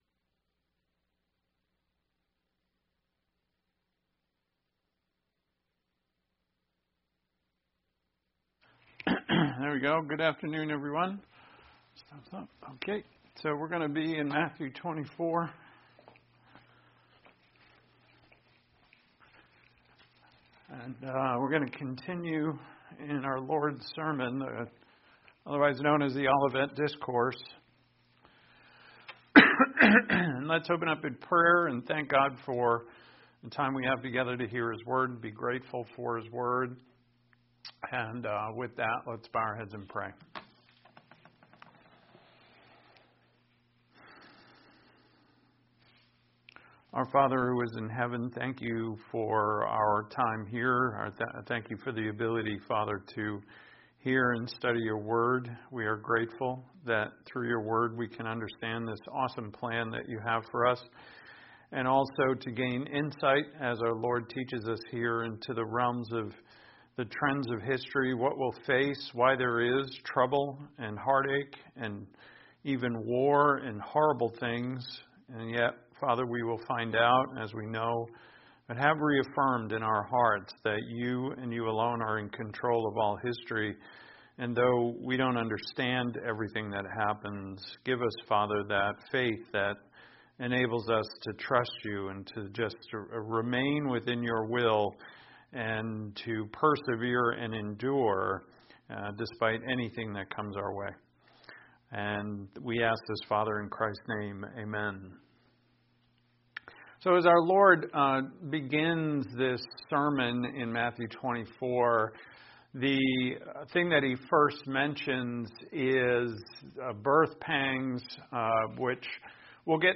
This sermon tells us what we face in the land that is suffering the birth pangs of the coming Messiah.